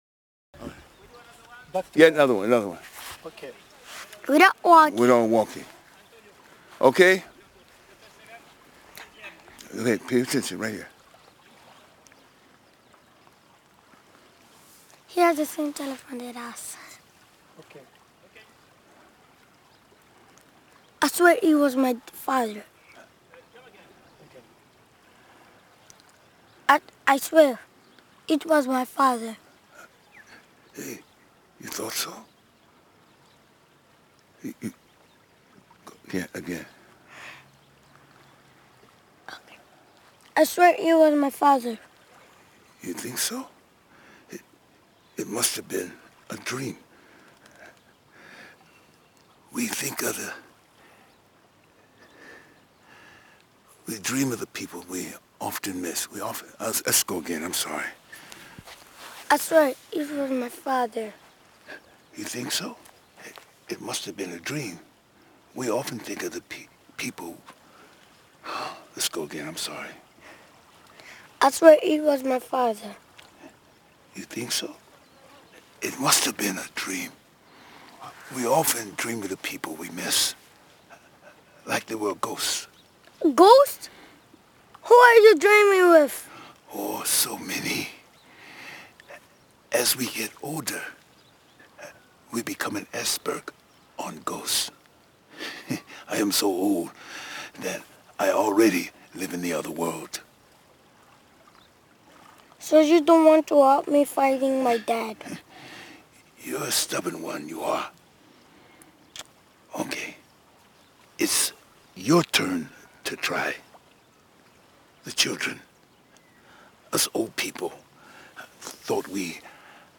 Même si je précède comme je peux les comédiens avec la perche, celle-ci s’avère inutile, ramenant trop de son de vague par rapport aux voix.
La République des enfants – 55 / 4 w2 – perche seule au centre